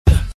Kicks
nt kick 6.wav